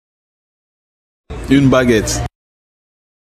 uitspraak Une baguette uitspraak Baguette uitspraak La Baguette